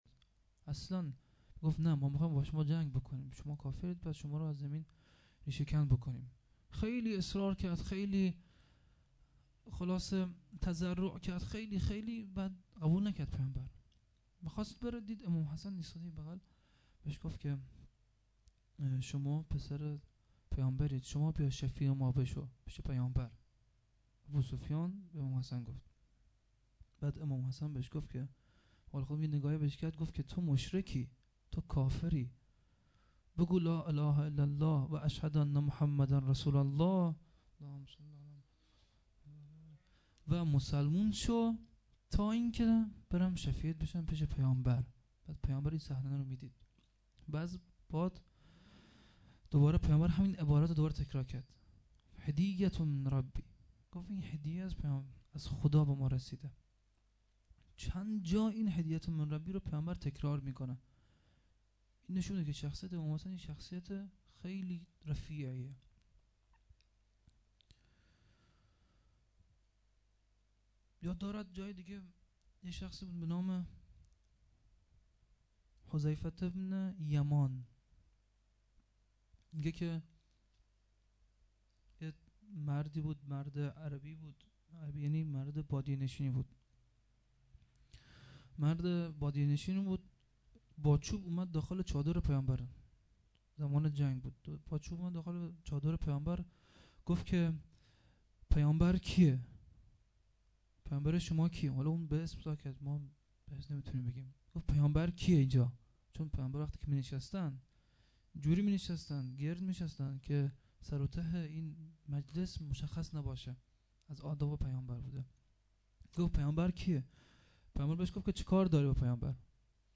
shadat-piambar-v-emam-hasan-92-sokhanrani-2.mp3